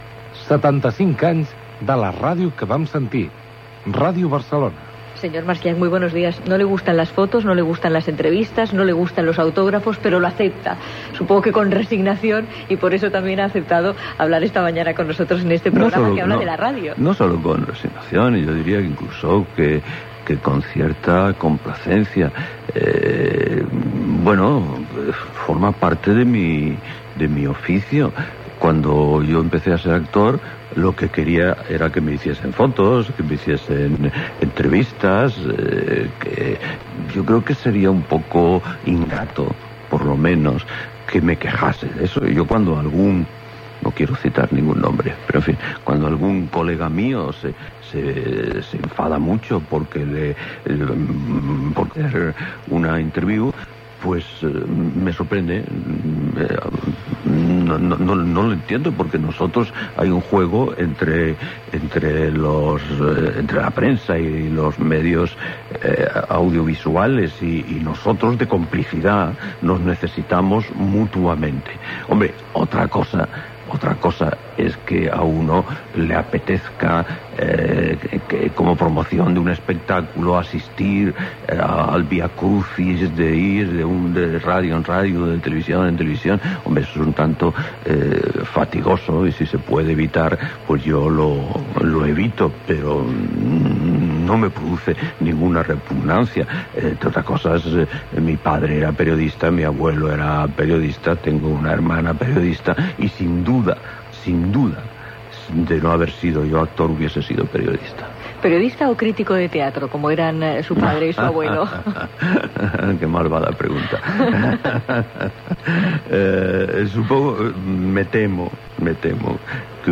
Indicatiu del programa, entrevista a l'actor i director teatral Adolfo Marsillach sobre els seus inicis a Ràdio Barcelona
Divulgació